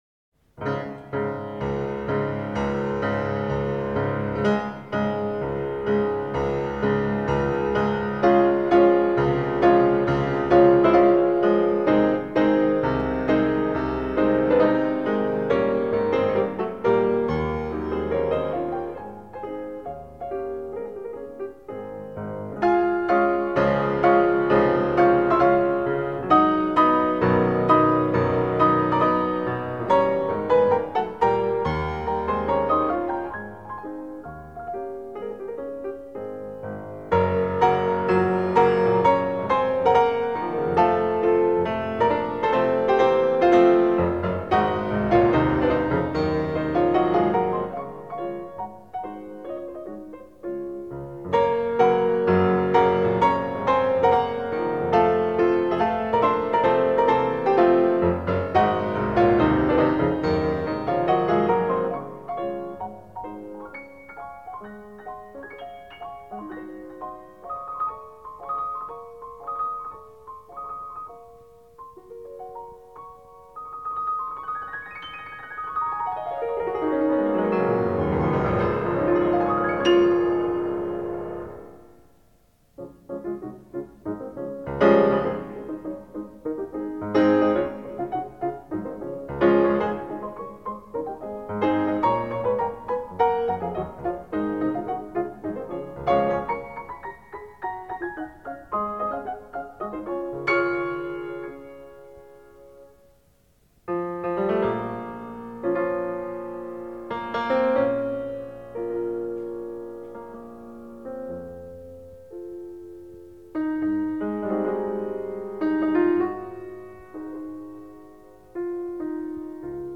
Martha Argerich, piano